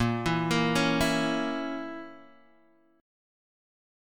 A#dim chord